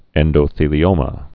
(ĕndō-thēlē-ōmə)